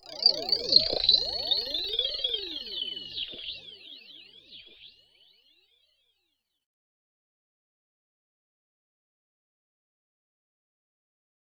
Transition [Tick Tock].wav